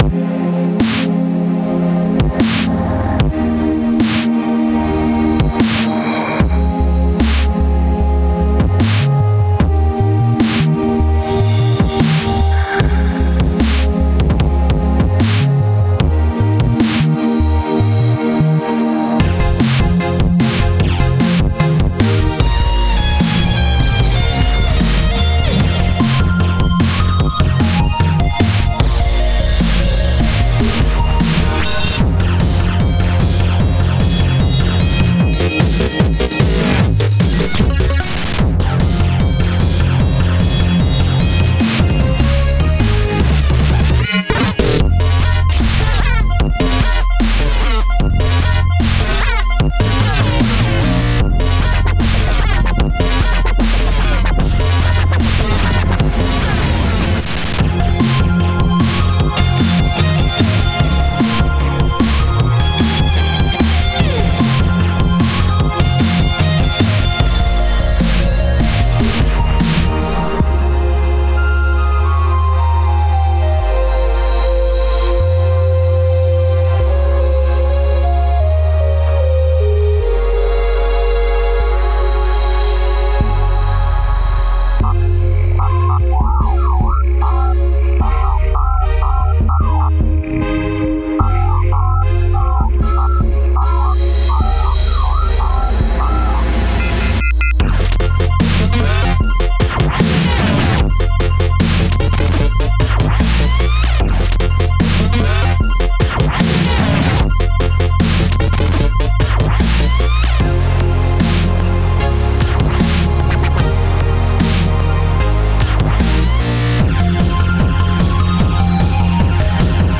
4/4 time, 150 bpm
This is another dubstep-rock-dance track.